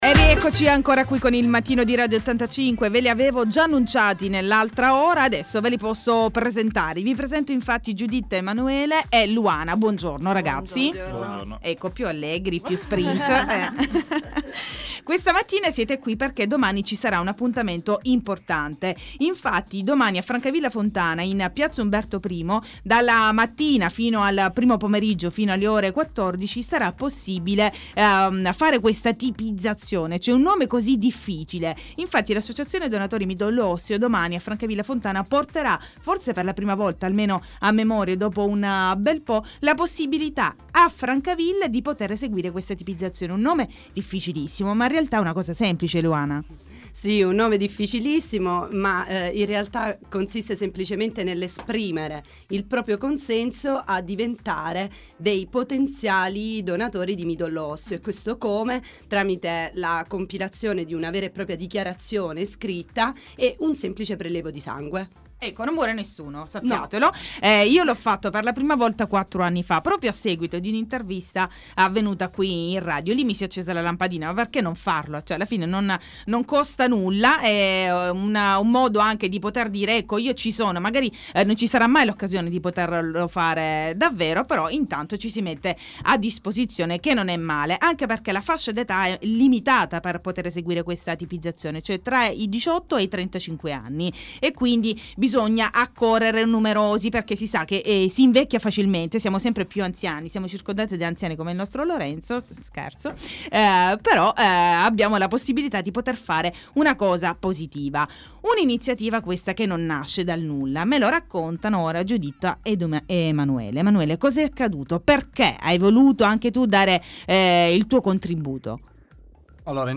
Il Mattino Di Radio85 – “Tipizziamoci” – intervista ai rappresentanti dell’Admo di Francavilla Fontana